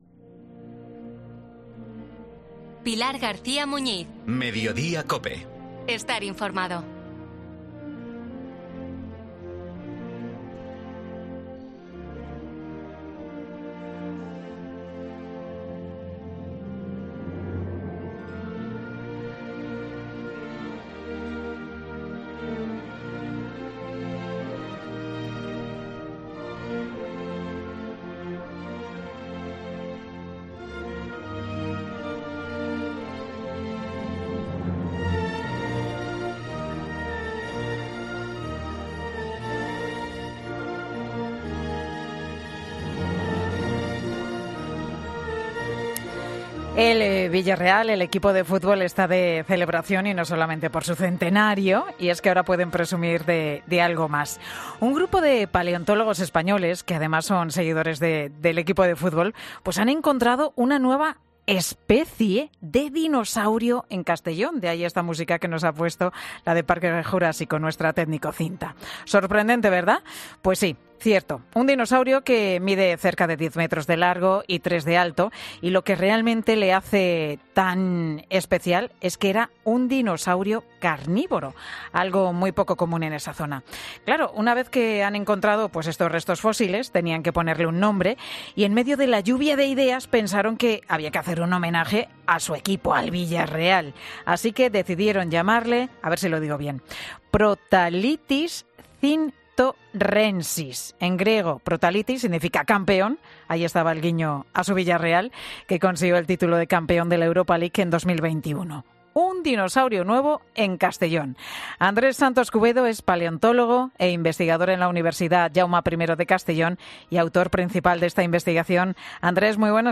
paleontólogo e investigador, en Mediodía COPE